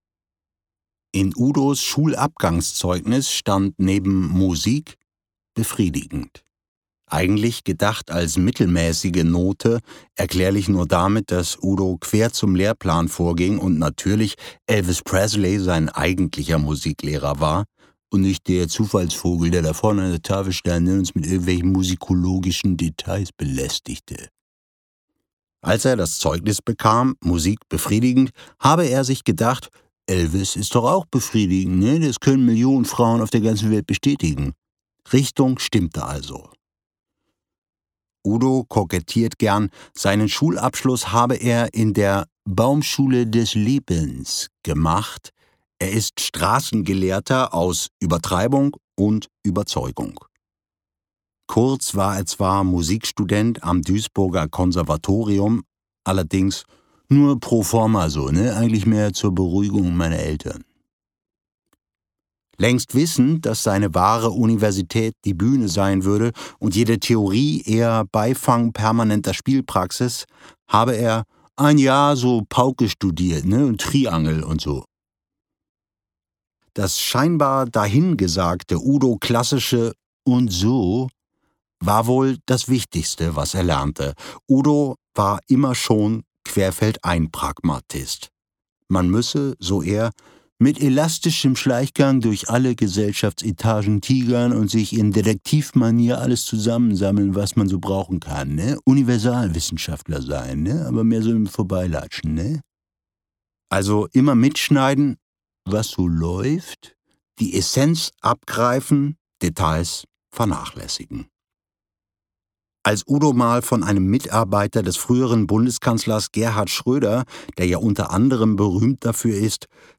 Gekürzt Autorisierte, d.h. von Autor:innen und / oder Verlagen freigegebene, bearbeitete Fassung.
Udo Fröhliche Gelesen von: Benjamin von Stuckrad-Barre